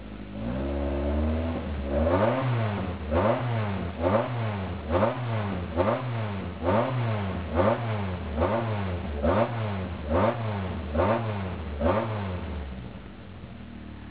ＥＮＧＩＮＥ
しかしレースエンジン特有の気難しさは無く低速からトップエンドまでストレス無く吹けあがり，きょうれつな排気音と重なり、かなり五感に訴えて来るそれは一度体験したら止められなくなります。